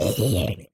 Sound / Minecraft / mob / zombie / death.ogg
should be correct audio levels.